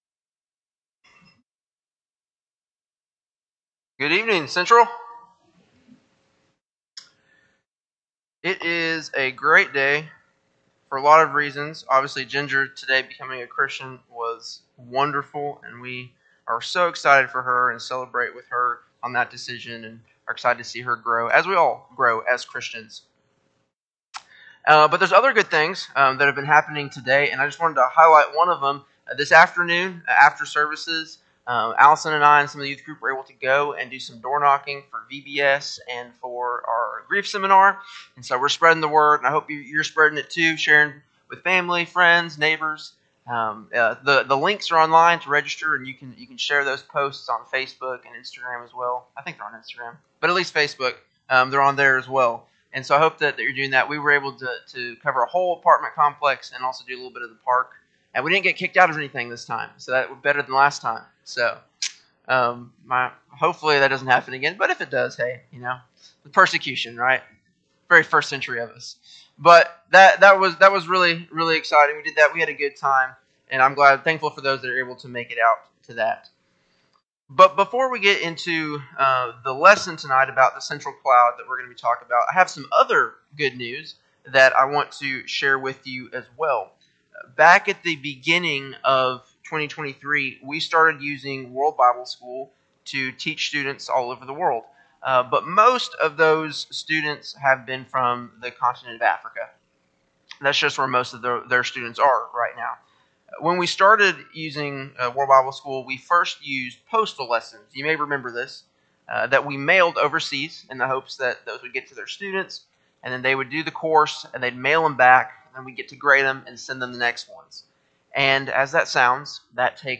4-21-24-Sunday-PM-Sermon.mp3